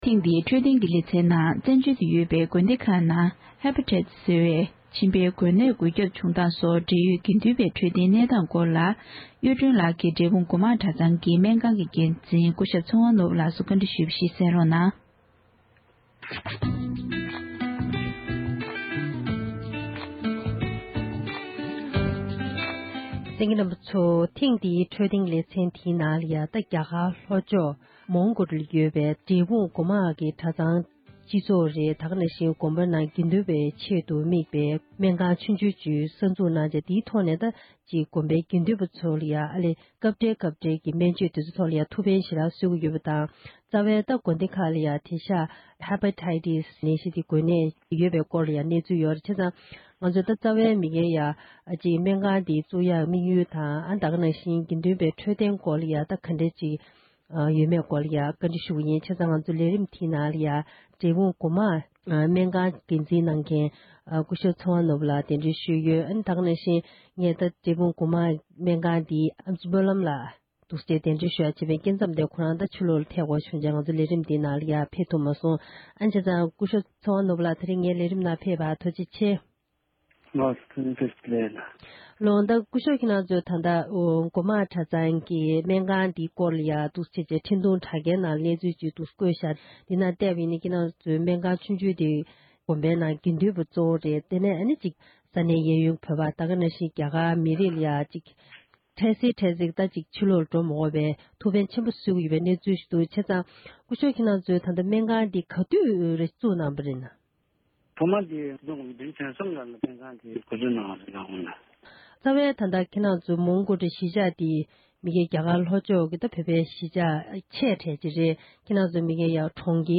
བཀའ་འདྲི་ཞུས་པ་ཞིག་གསན་རོགས༎